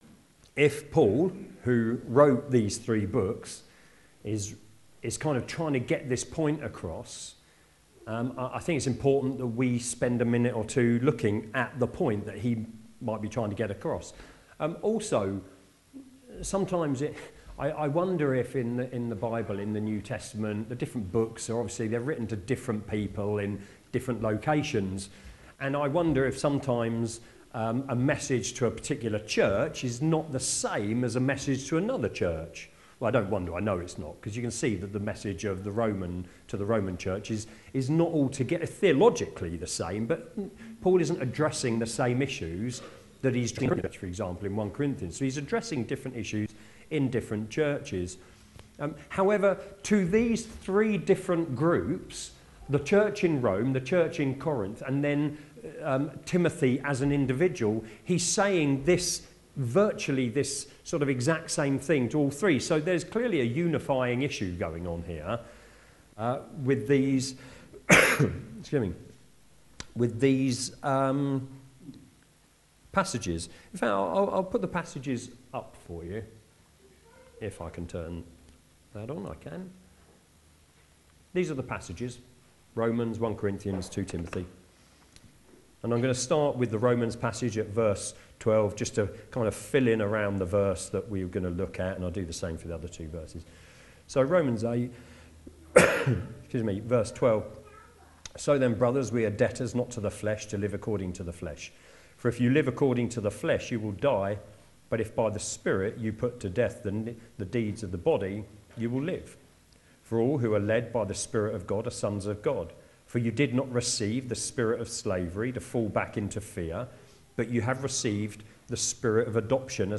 Sermon from Sunday 25th May (Romans 8)